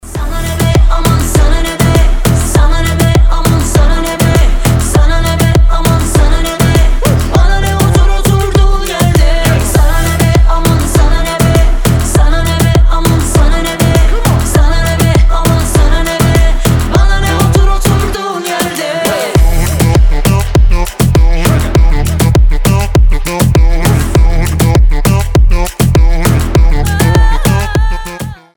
• Качество: 320, Stereo
громкие
заводные
dancehall
Dance Pop
восточные
Moombahton
красивый женский вокал